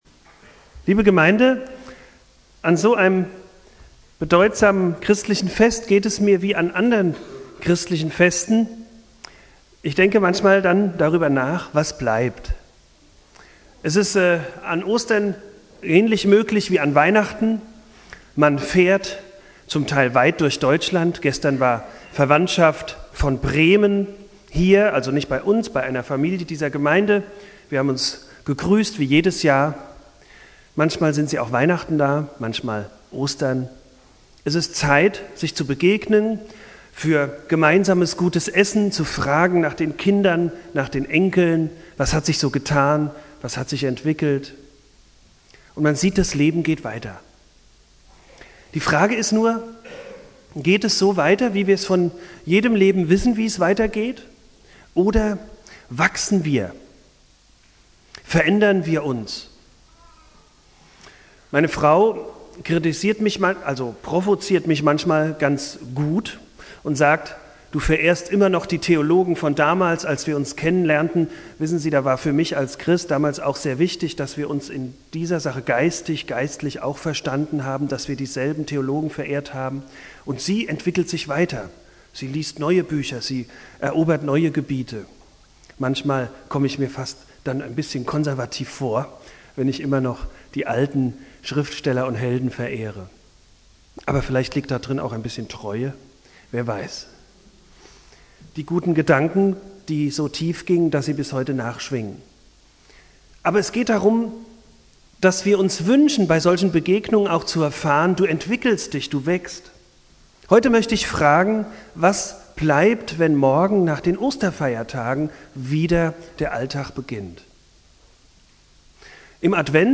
Predigt
Ostermontag Prediger